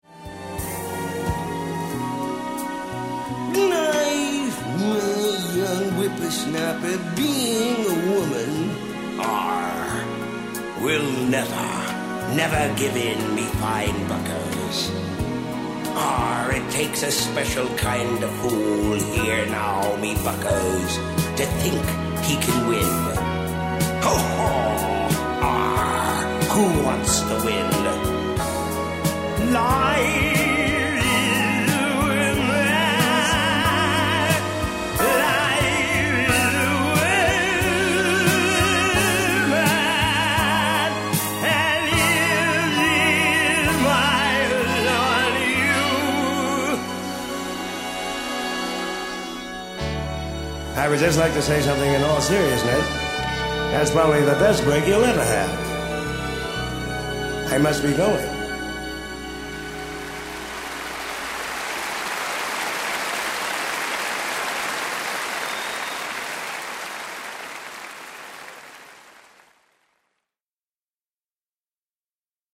Sammy Davis Jr. really loved screwing around in the studio.
Talking Like a Pirate - Sammy Davis Jr.mp3